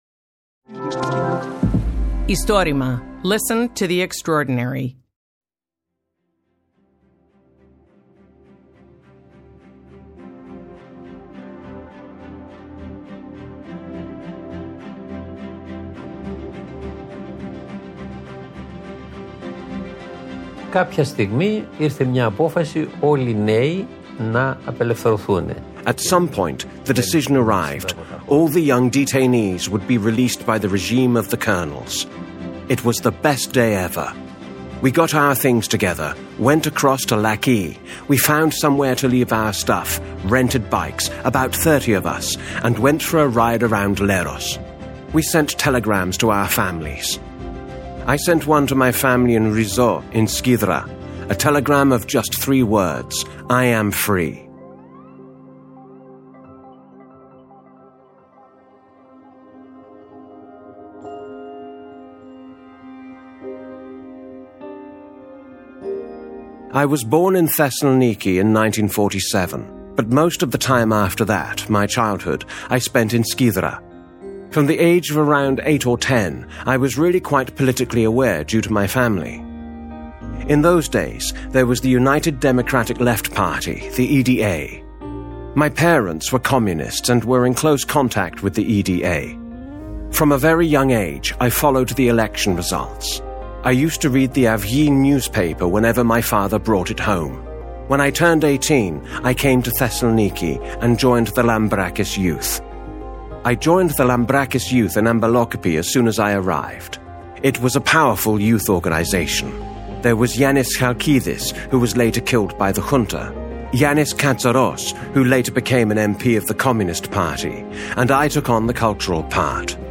Istorima is the bigest project of recording and preserving oral histories of Greece.
Voiceover